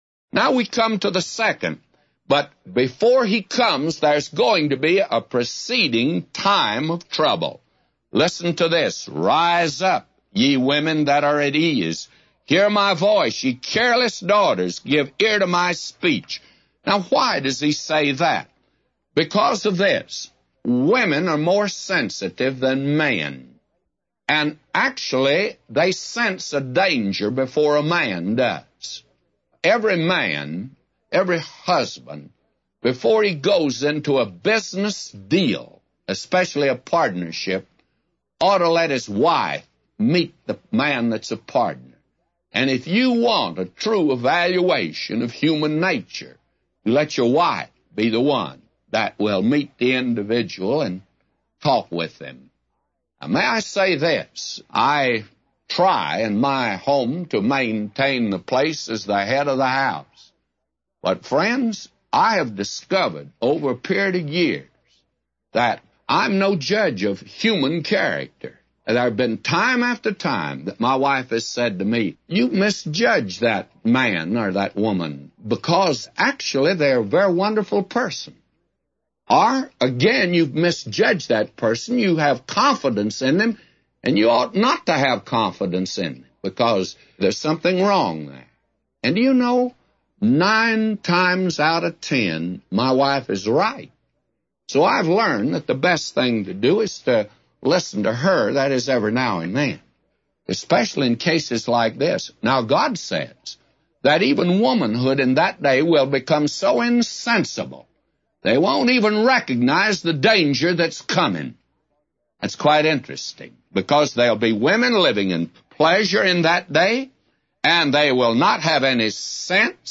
A Commentary By J Vernon MCgee For Isaiah 32:9-999